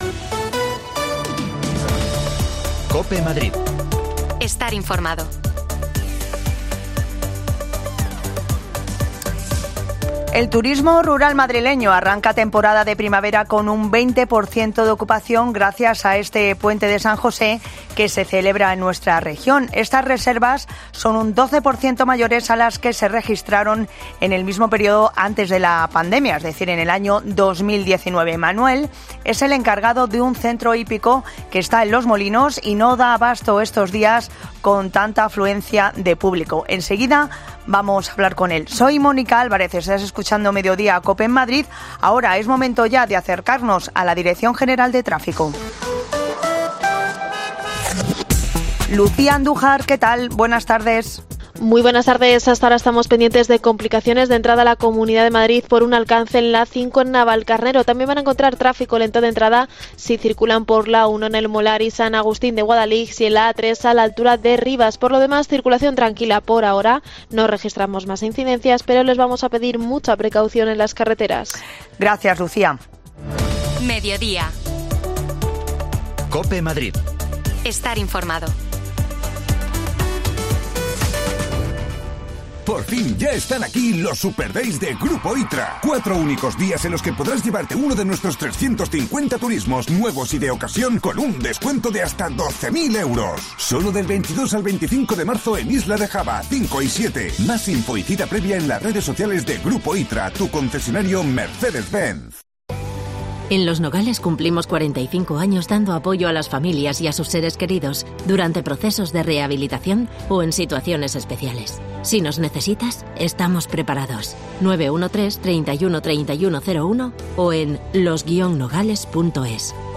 Nosotros nos hemos ido a Los Molinos
Las desconexiones locales de Madrid son espacios de 10 minutos de duración que se emiten en COPE , de lunes a viernes.